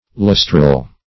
Lustral \Lus"tral\, a. [L. lustralis, fr. lustrum: cf. F.